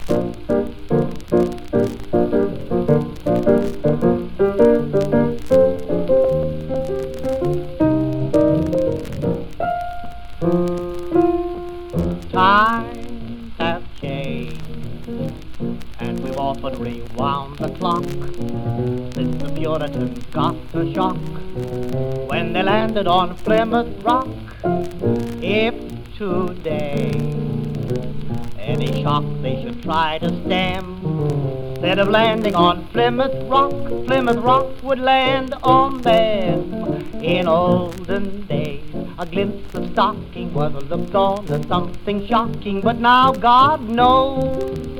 Jazz, Pop　USA　12inchレコード　33rpm　Mono
ジャケ汚れ　盤良好レーベル部シール貼付有　見開きジャケ　元音源に起因するノイズ有